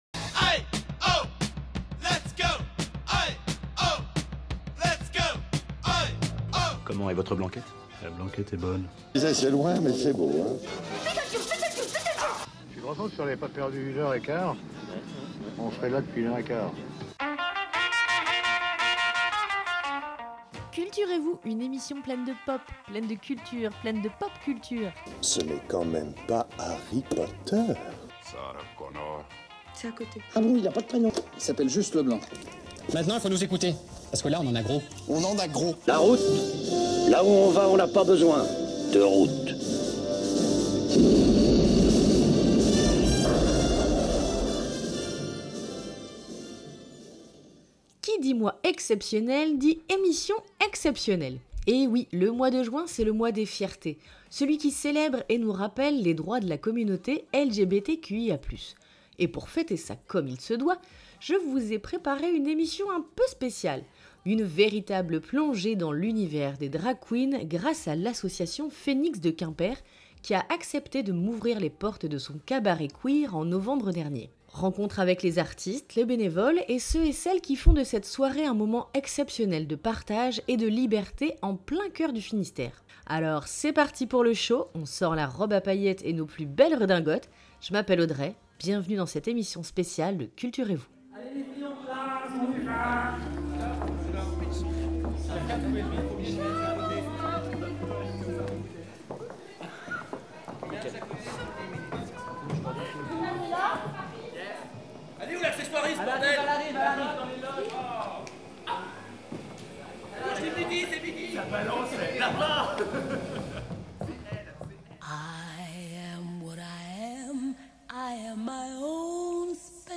Au programme de cette émission : une immersion dans le monde du drag queen, grâce aux artistes et aux bénévoles qui ont accepté de m’ouvrir les portes d’une soirée pas comme les autres.